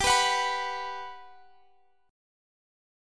GM7 Chord (page 3)
Listen to GM7 strummed